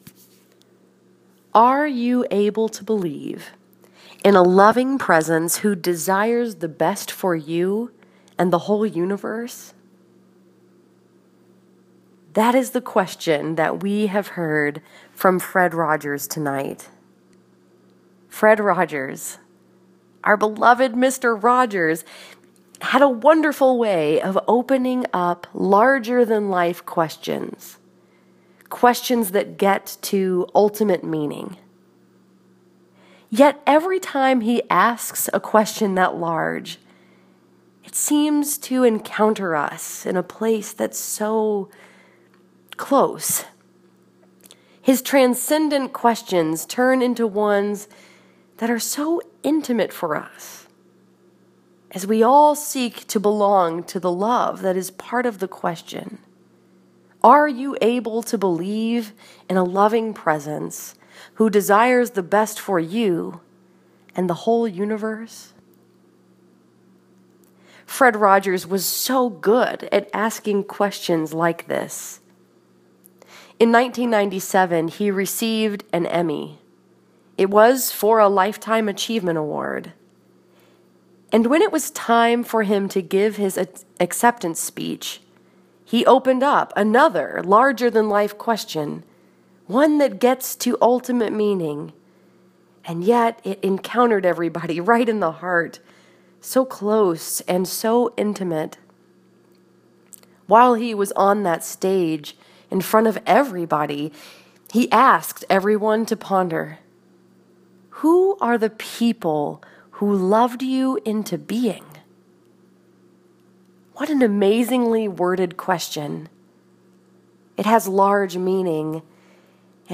Tonight, I had the great privilege to speak about the life and witness of Fred Rogers during the Sunday evening Jazz Mass at Canterbury House.
Each Sunday night at 5pm, they have a Jazz mass with glorious music that is spirited and playful.